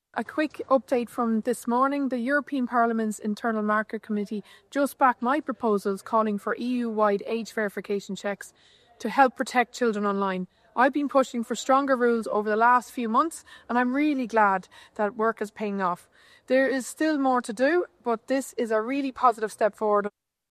Speaking in Brussels, MEP Carberry says the vote is a positive step forward in ensuring a consistent EU approach to online child protection: